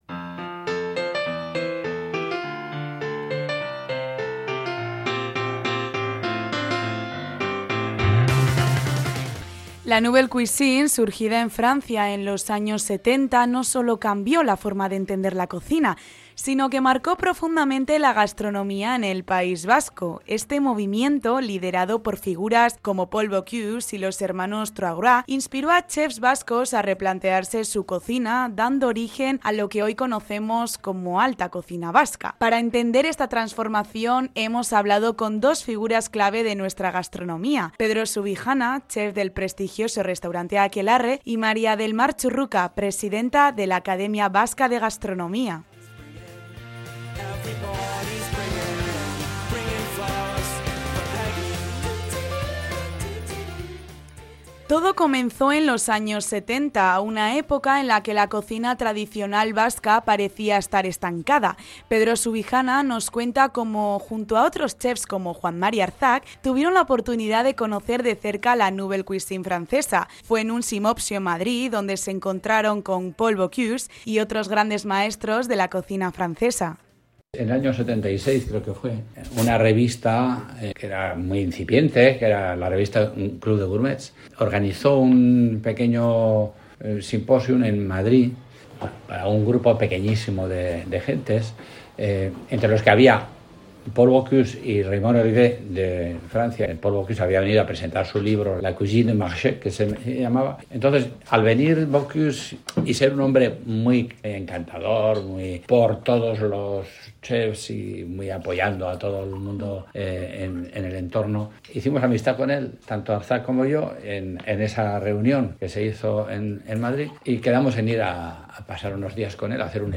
Reportaje sobre la Nouvelle Cuisine
REPORTAJE-NOUVELLE-CUISINE.mp3